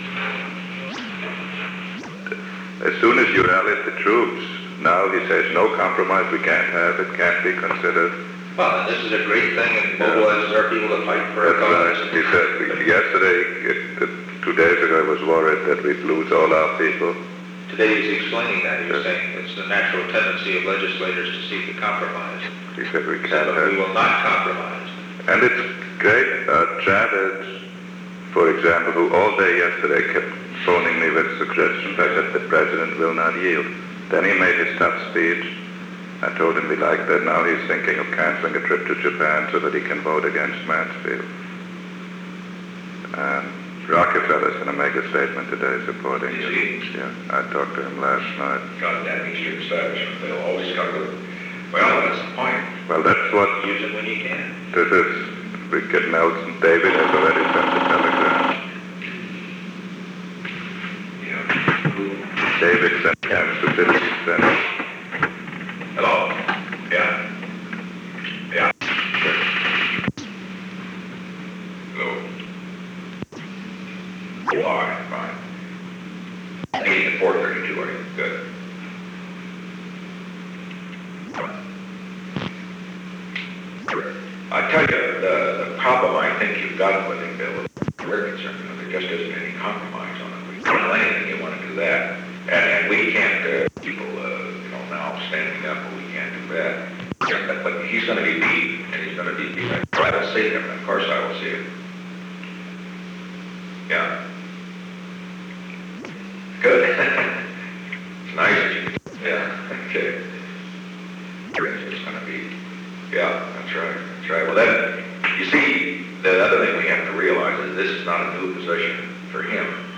On May 13, 1971, President Richard M. Nixon, Henry A. Kissinger, H. R. ("Bob") Haldeman, William P. Rogers, and Alexander P. Butterfield met in the Oval Office of the White House from 9:28 am to 10:03 am. The Oval Office taping system captured this recording, which is known as Conversation 498-002 of the White House Tapes.
The recording began while the meeting was in progress. Discontinuities appear in the original recording